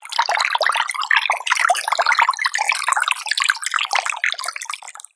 水の流れる音。